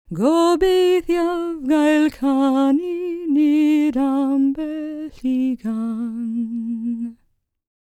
L CELTIC A16.wav